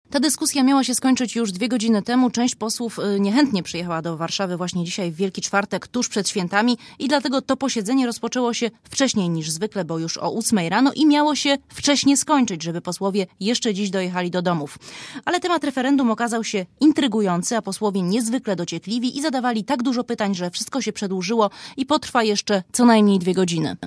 Relacja Radia Zet (190Kb)